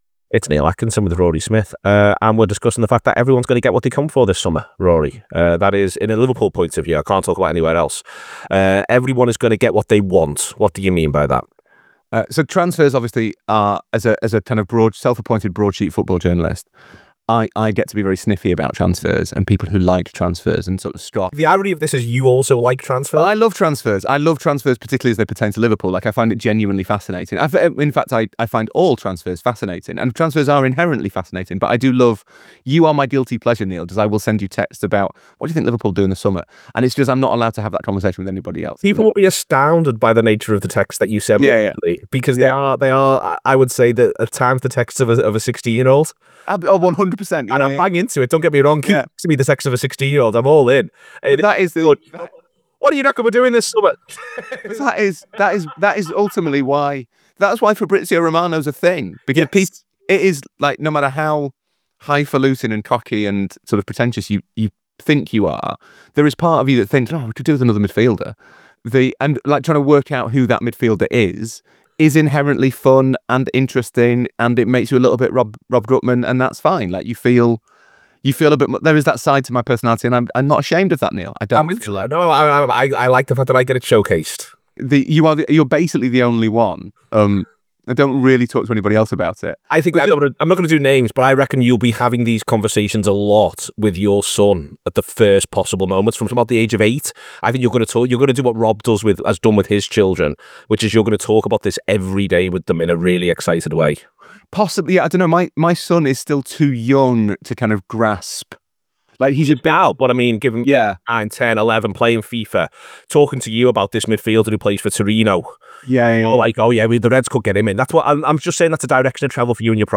Below is a clip from the show – subscribe for a look at Liverpool’s summer…